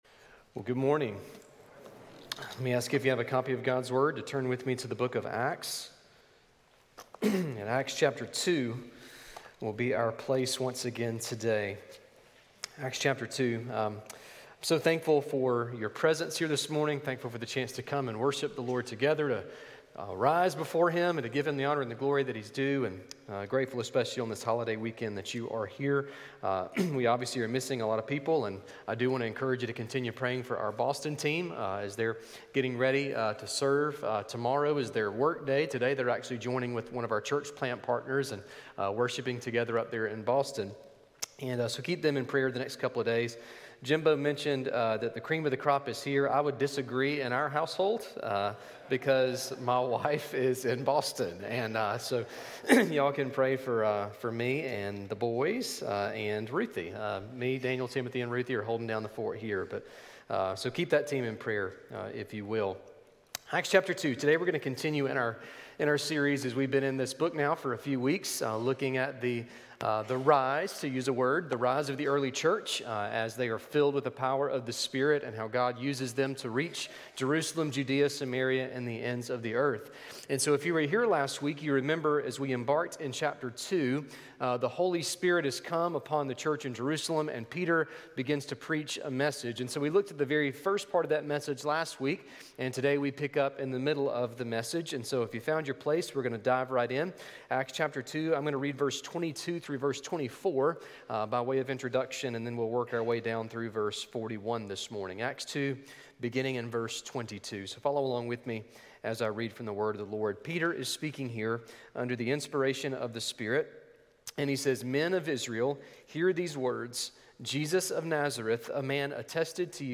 Sermon Listen Service Scripture References